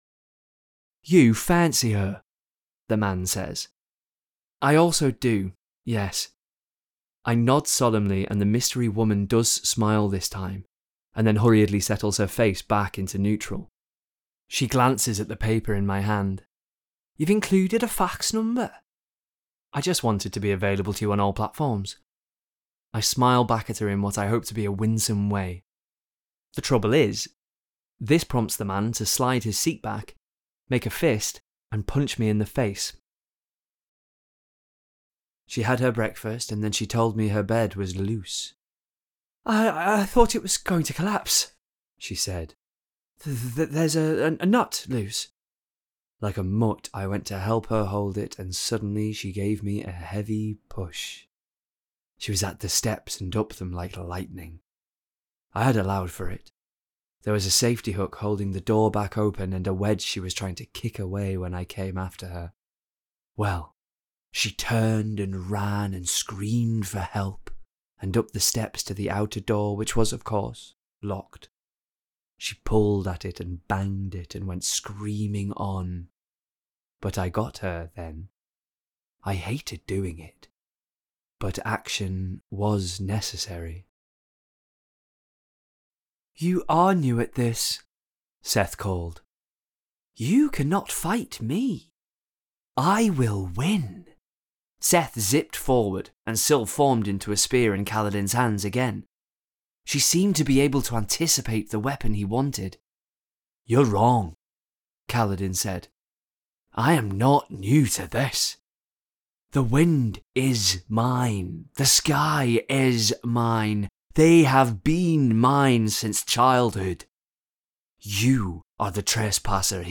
Audiobook Reel
• Native Accent: Teesside
His versatility shines through as he seamlessly transitions from embodying the relatable everyman to a downtrodden soldier to a sinister villain, delivering each role with care and authenticity.